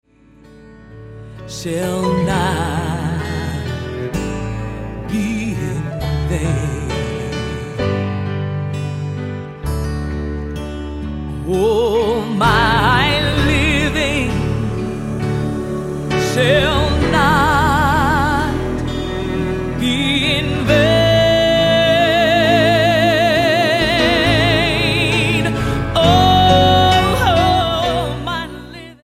STYLE: Gospel
with a band re-creating a timeless, bluesy accompaniment